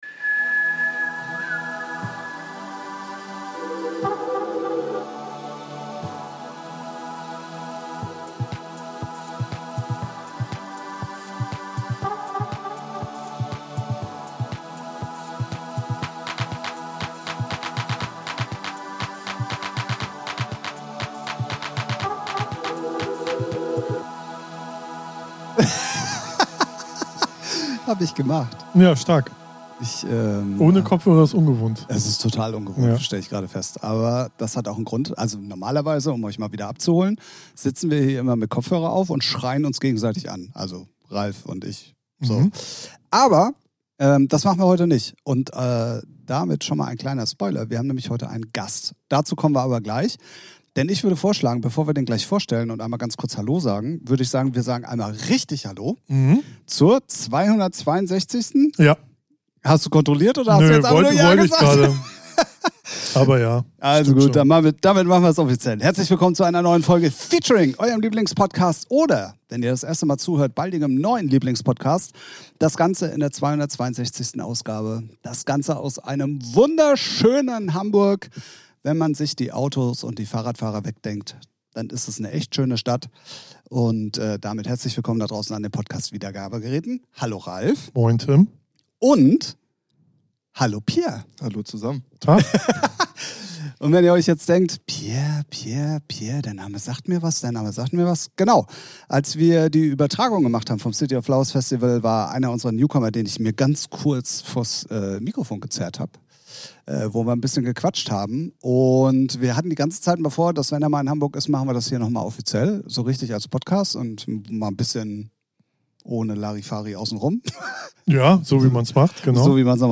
In der neuesten Folge haben wir wieder einen Gast am Start.
Natürlich gibt es auch die volle Packung Musik für euch, die ihr in unserer Playlist findet.